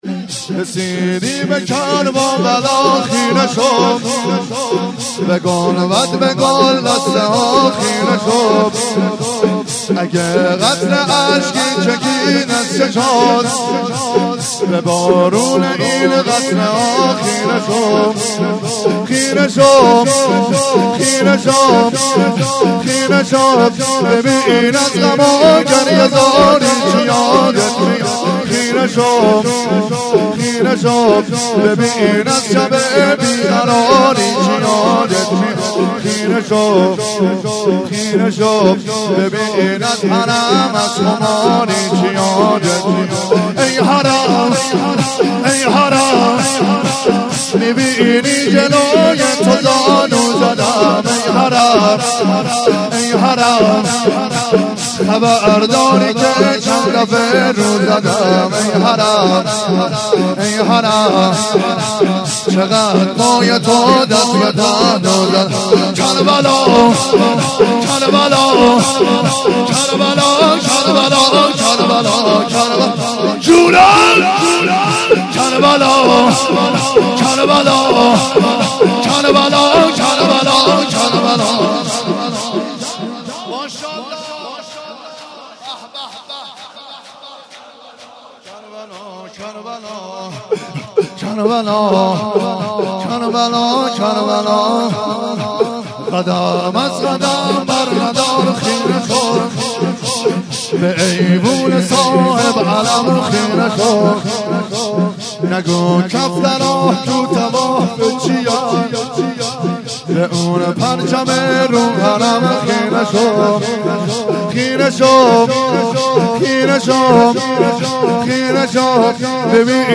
4- رسیدی به کرب و بلا خیره شو - شور